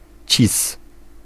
Ääntäminen
Synonyymit ive if commun Ääntäminen France: IPA: [if] Paris Haettu sana löytyi näillä lähdekielillä: ranska Käännös Konteksti Ääninäyte Substantiivit 1. cis {m} kasvi Suku: m .